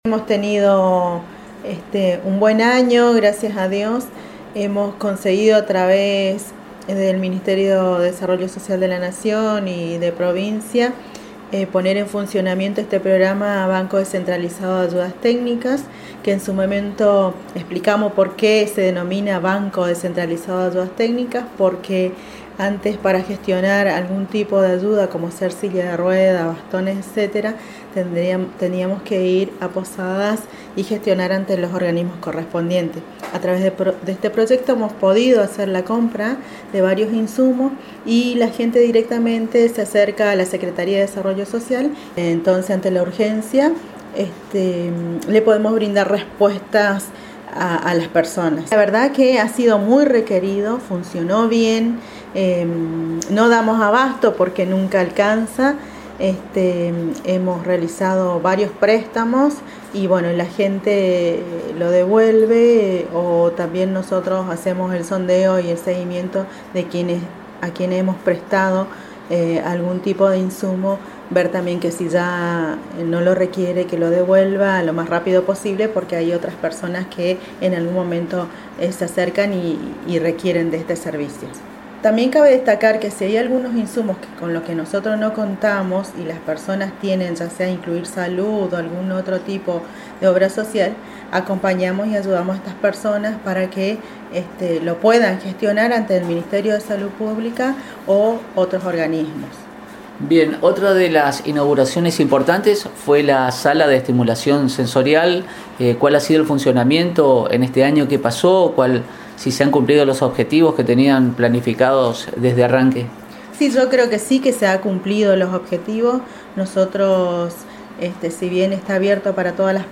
En charla exclusiva con la secretaría de Desarrollo Social de la municipalidad de Apóstoles Itatí Maidana dejó detalles del funcionamiento del banco descentralizado de ayudas técnicas y la sala de estimulación sensorial, inauguradas este año con la presencia del Vicegobernador Oscar Herrera Ahuad, con los objetivos que se propusieron cumplidos según relató la funcionaria.